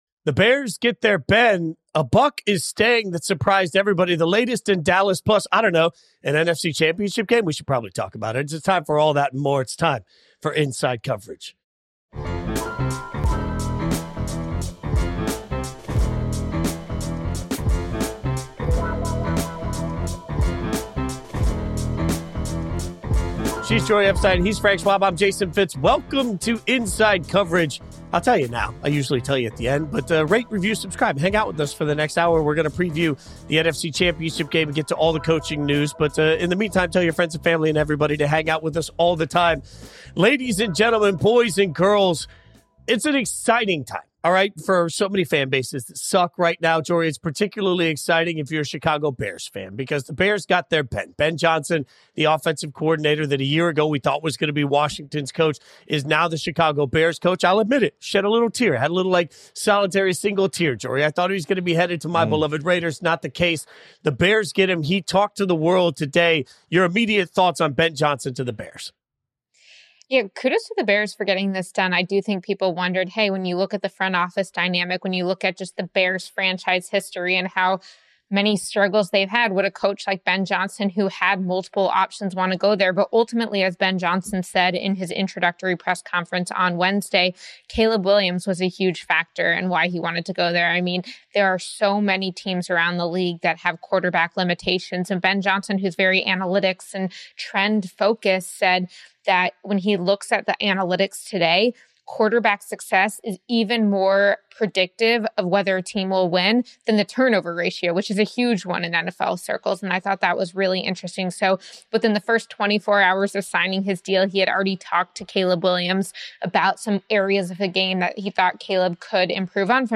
(including some breaking news live on the show)